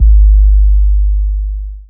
Subby808_YC.wav